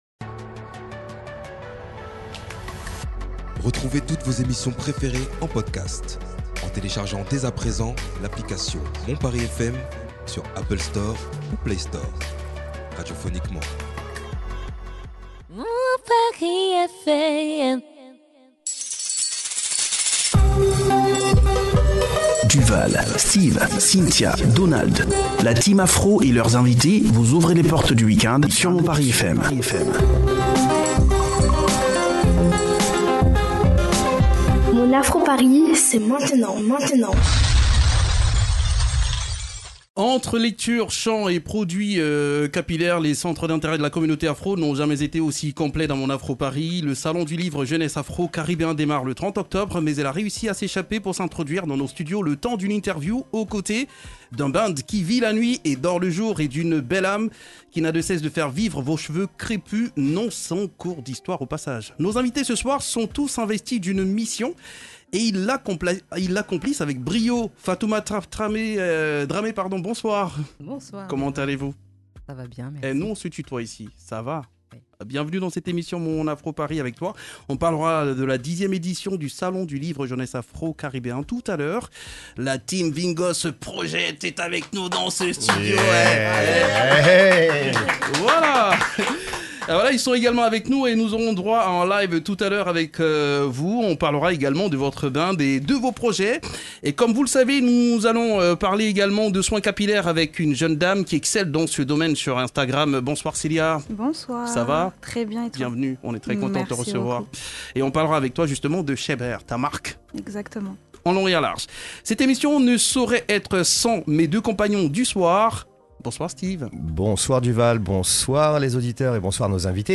Le salon du livre jeunesse afro caribéen démarre le 30 octobre, mais elle a réussi à s’échapper pour s’introduire dans nos studios le temps d’une interview aux côtés d’un band qui vie la nuit et dortle jour et d’une belle âme qui n’a de cesse de faire vivre vos cheveux crépus, non sans un cours d’histoire au passage.